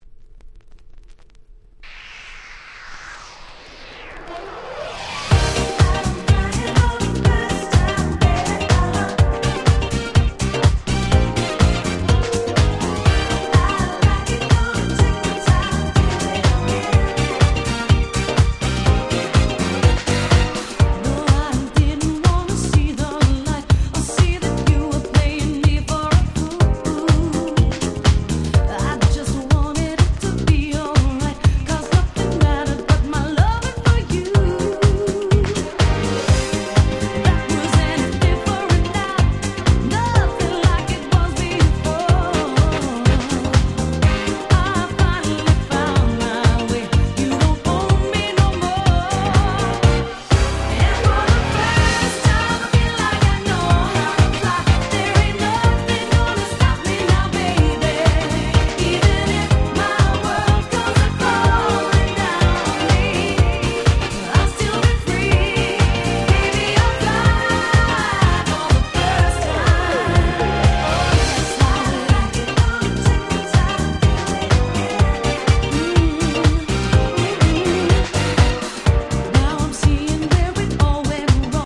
00' Nice EU R&B !!
キャッチー系